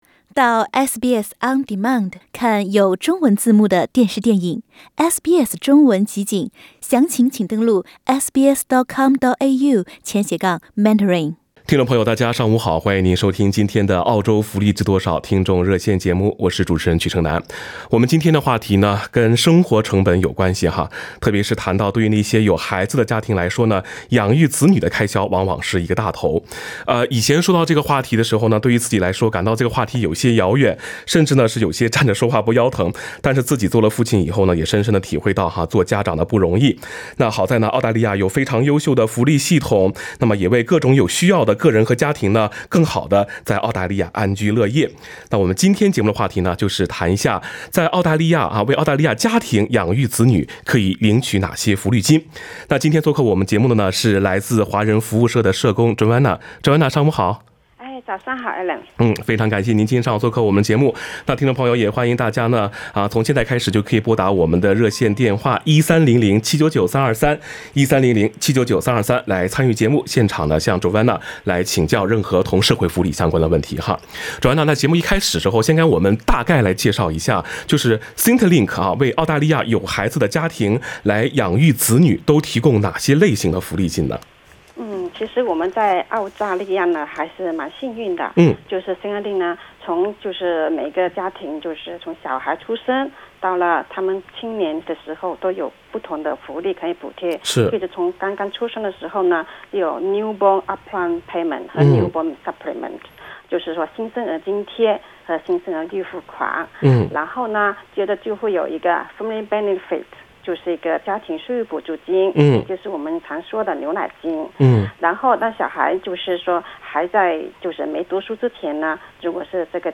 听众热线节目